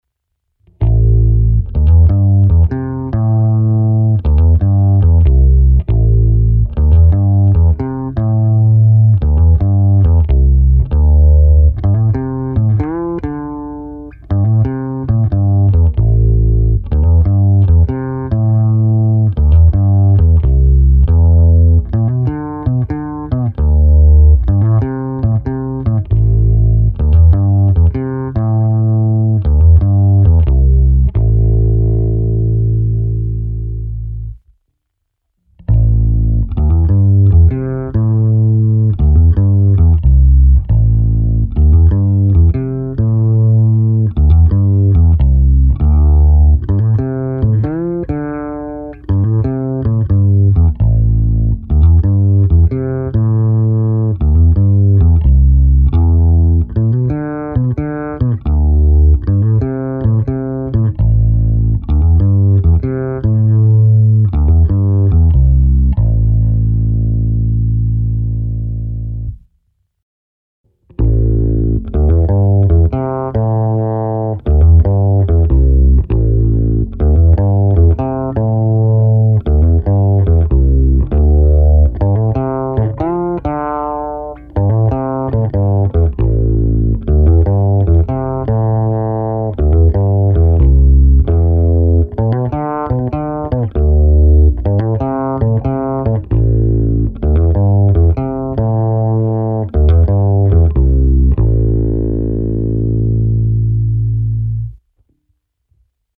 Nahrávky v pořadí: krkový snímač - oba snímače - kobylkový snímač. Vše hráno blízko krku. Struny niklové roundwound DR Sunbeams.
To samé se simulací aparátu